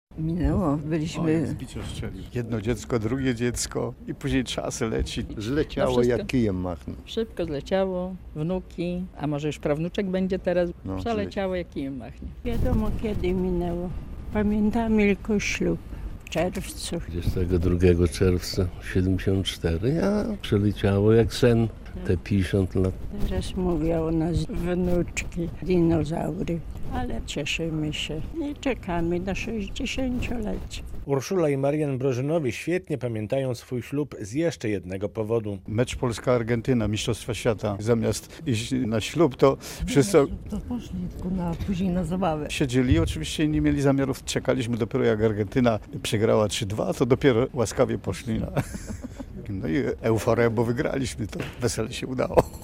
Trzydzieści dwie pary odebrały w Hali Kultury medale "Za Długoletnie Pożycie Małżeńskie".
Razem przez pół wieku. Złote Gody w Łomży - relacja